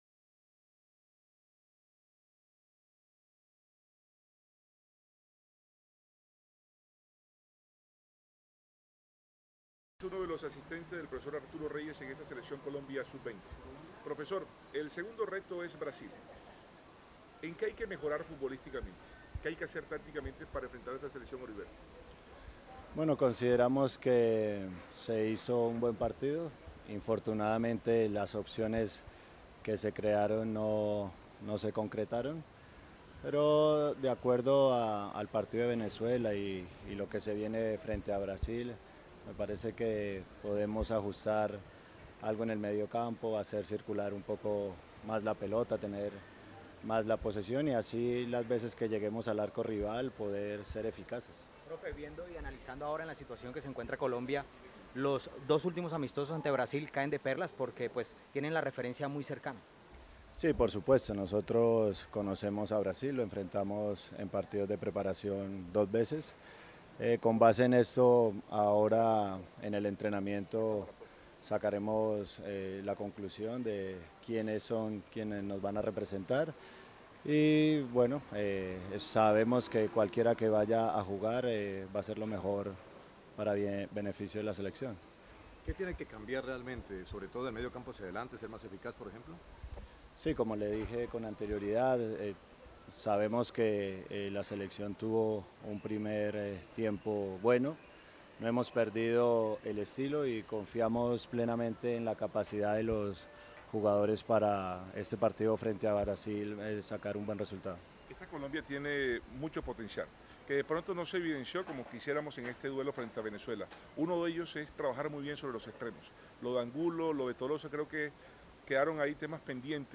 atendieron a la prensa:
(Asistente Técnico)